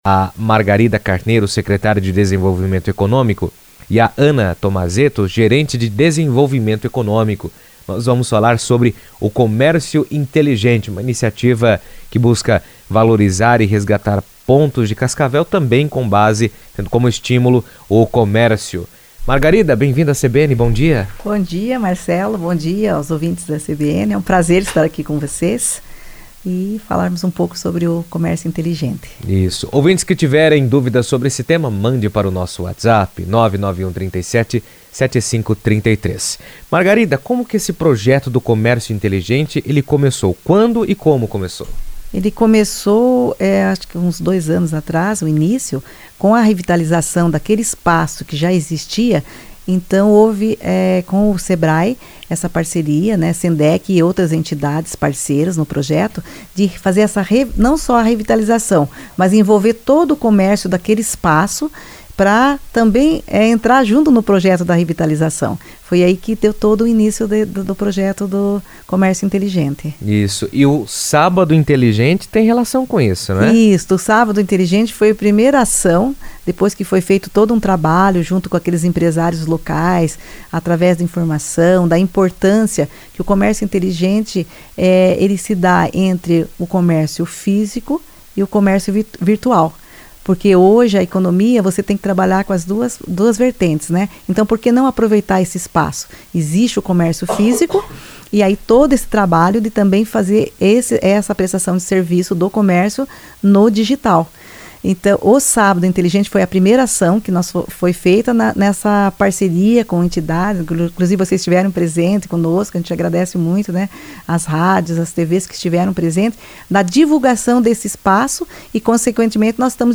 O projeto Comércio Inteligente busca modernizar o setor em Cascavel por meio de tecnologia, inovação e capacitação de empresários, promovendo melhorias inteligentes e oferecendo experiências de compra mais atraentes e digitais aos consumidores, com destaque para o evento “Sábado Inteligente”. O tema foi comentado na CBN Cascavel